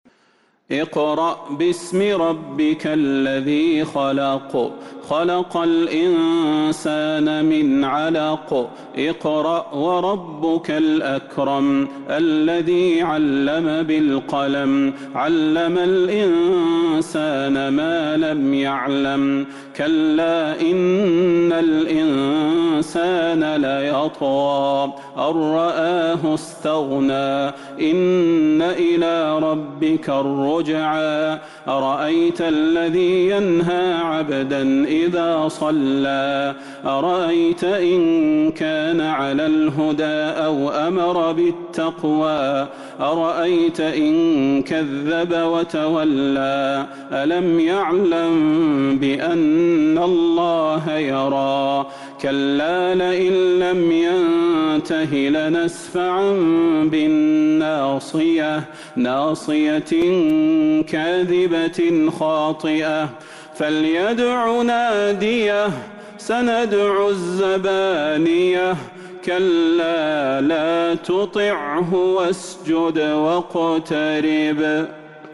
سورة العلق Surat Al-'Alaq من تراويح المسجد النبوي 1442هـ > مصحف تراويح الحرم النبوي عام ١٤٤٢ > المصحف - تلاوات الحرمين